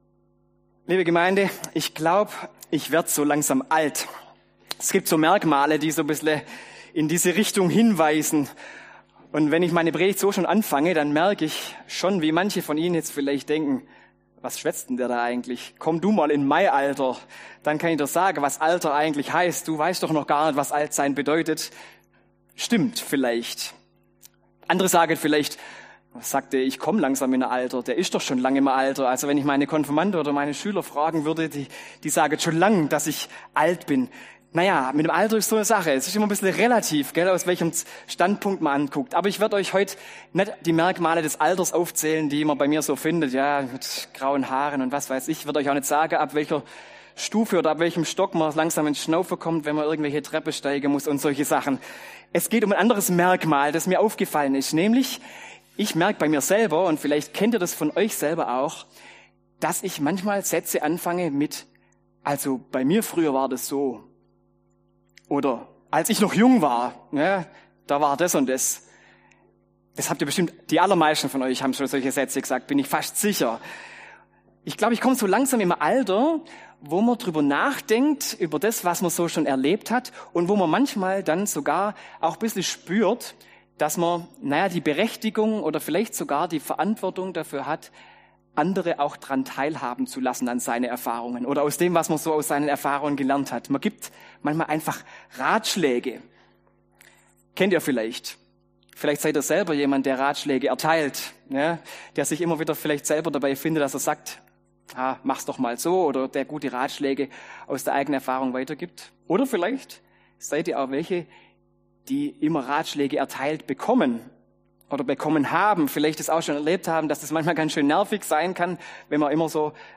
Predigt zu Ratschlägen von Älteren an Jüngere.